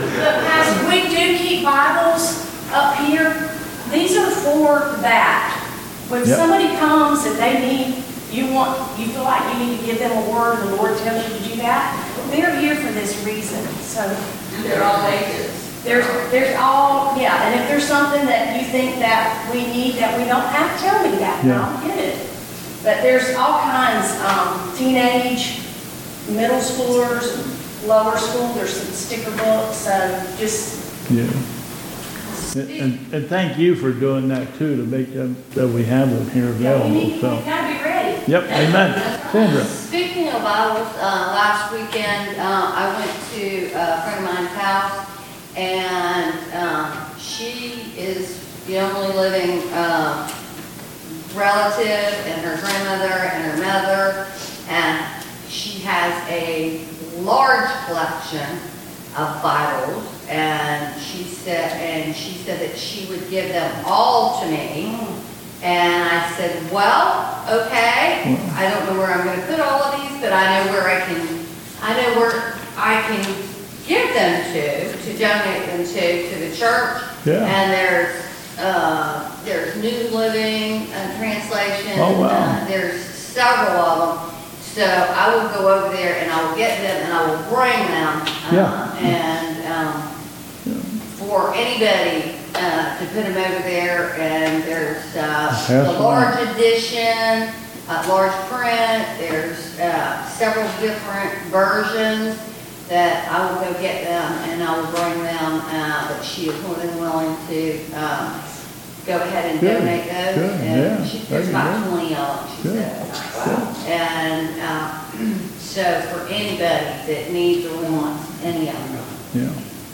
2022 Bethel Covid Time Service